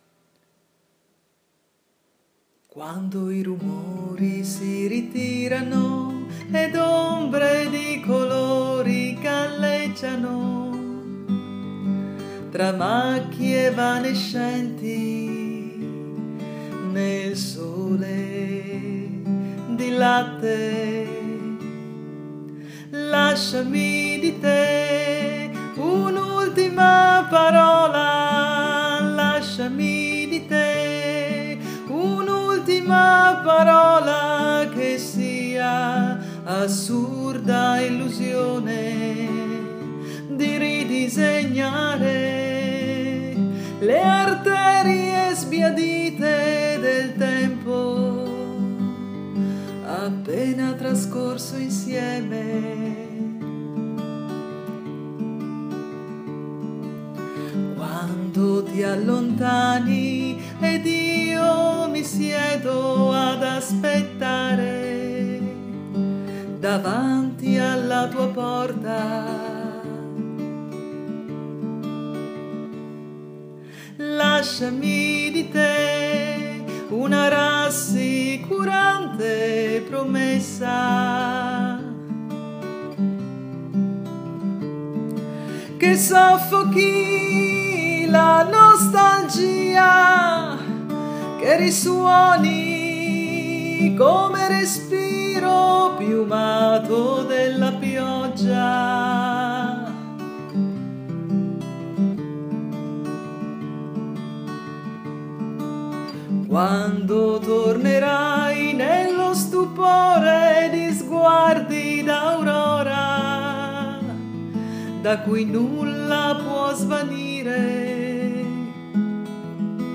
canzone composta e cantata